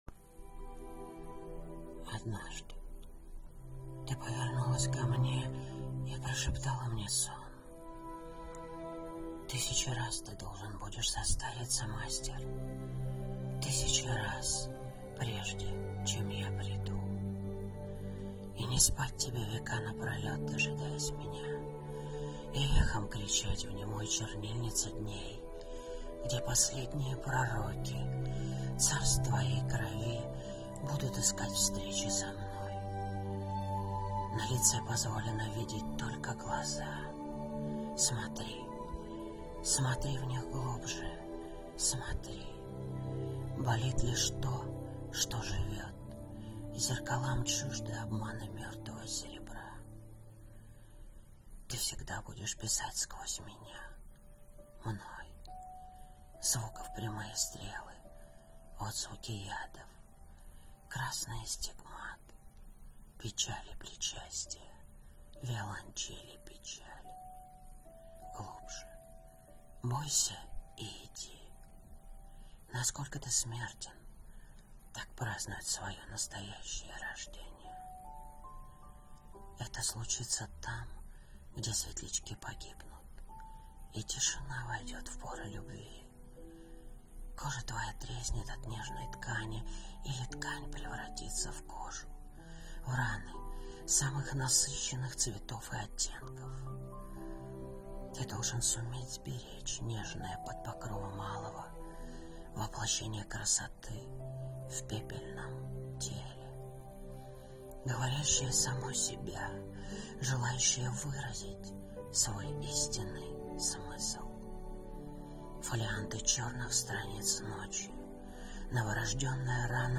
Стихи